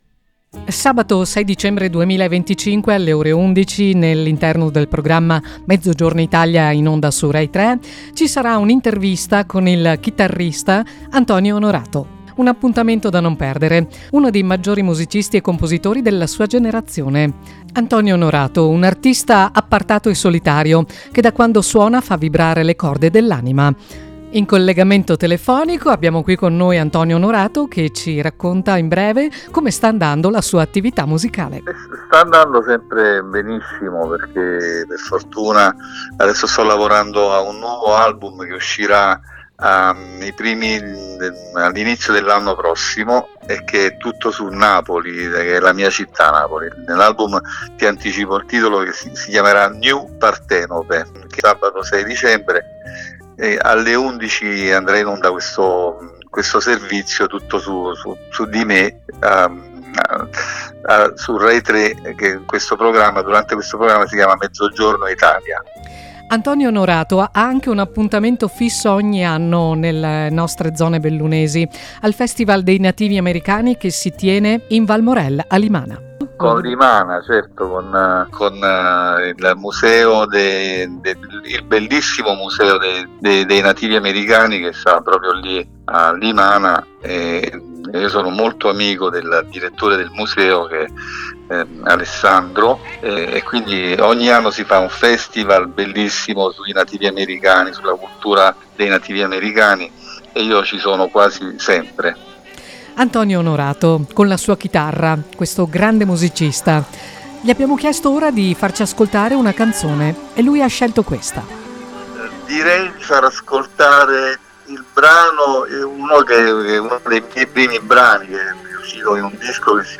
Anche Radio Più lo ha intervistato.